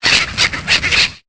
Cri de Vostourno dans Pokémon Épée et Bouclier.